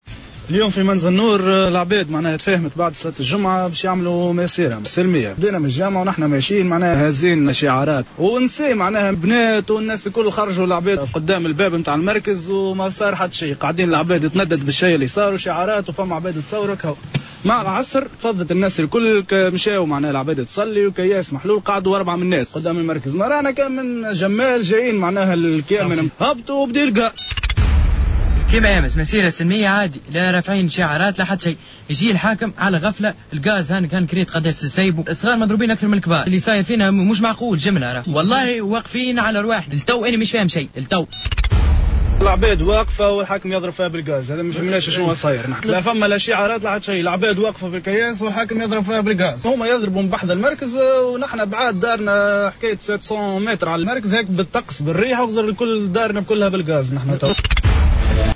شهادات أهالي منزل نور